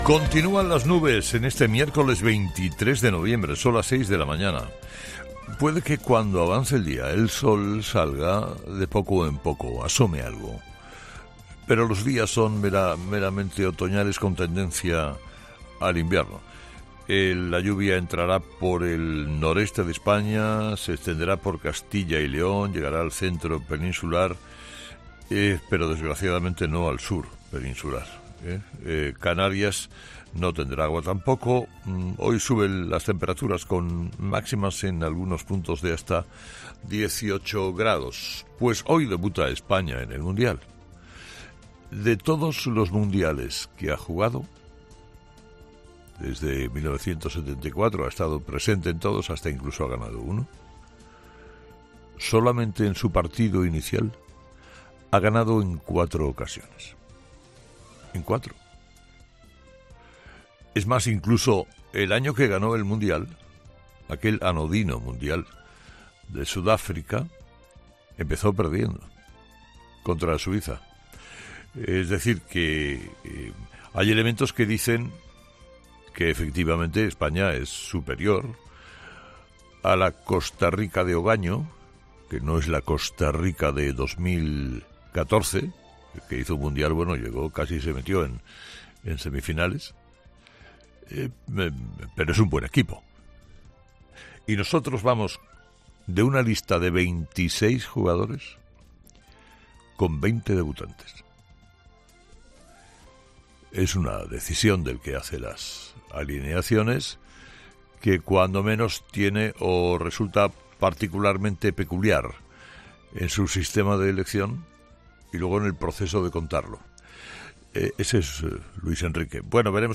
Carlos Herrera, director y presentador de 'Herrera en COPE', ha comenzado el programa de este miércoles analizando las principales claves de la jornada, que pasan, entre otros asuntos, por el debut de España en la selección y las claves del cara a cara entre Sánchez y Feijóo en el Senado.